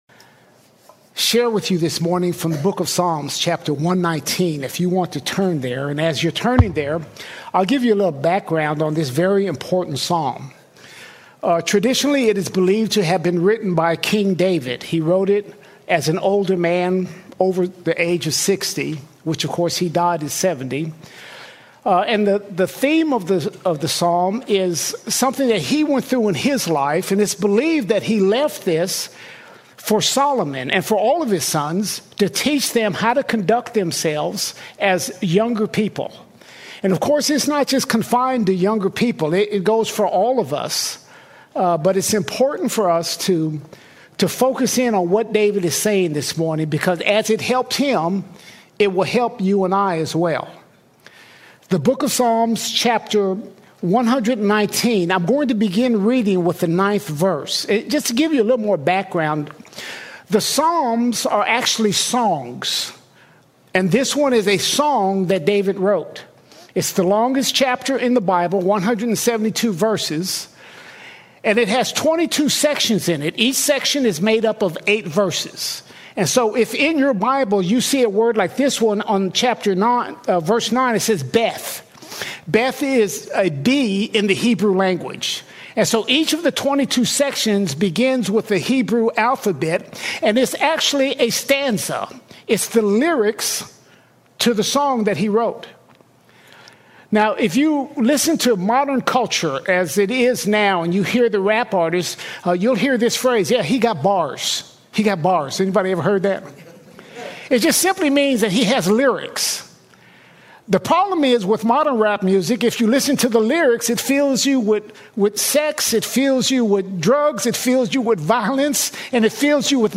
20 April 2026 Series: Sunday Sermons All Sermons Battling Spiritual Drift Battling Spiritual Drift When spiritual drift sets in, the answer is found in God’s Word.